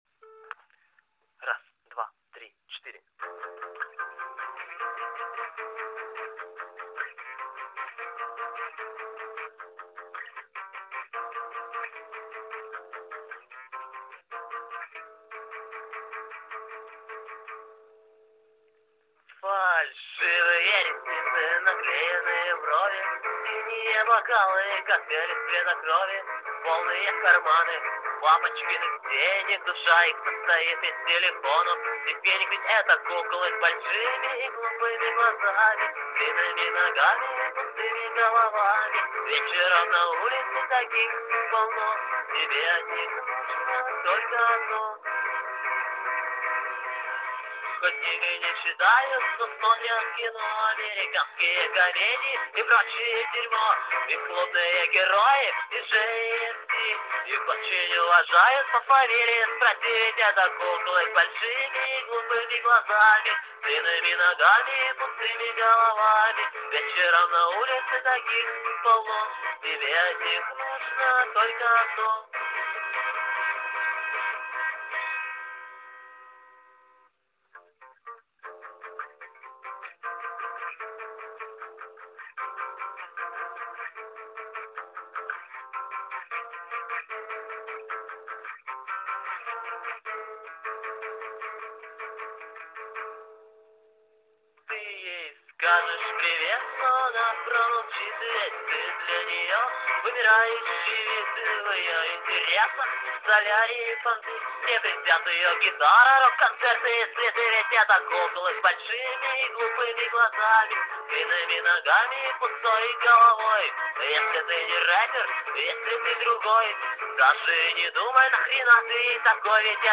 Да, гитара недонастроеная, и если чесно вообще дрова.